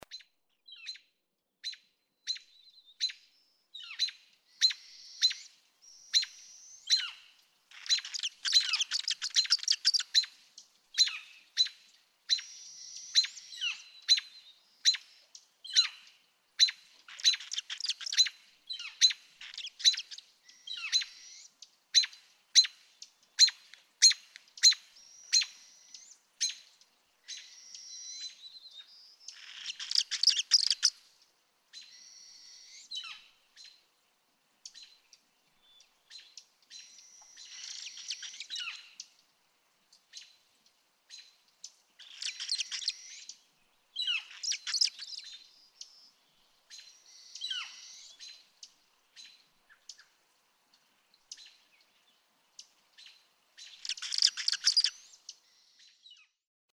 ♫20. Calls, sounding perturbed; an adult northern hawk owl perches and calls overhead (e.g., at 0:08, 0:17, 0:30, and more) and young hawk owls wheeze down below, requesting a meal (e.g., 0:05, 0:06). A northern flicker also calls throughout the recording (e.g., at 0:04, 0:07, 0:08 . . . to 0:47).
Slana, Alaska.
020_American_Robin.mp3